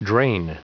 Prononciation du mot drain en anglais (fichier audio)
Prononciation du mot : drain